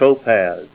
Help on Name Pronunciation: Name Pronunciation: Topaz